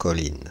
Ääntäminen
Ääntäminen Paris Tuntematon aksentti: IPA: /kɔ.lin/ Haettu sana löytyi näillä lähdekielillä: ranska Käännös 1. colina {f} Suku: f .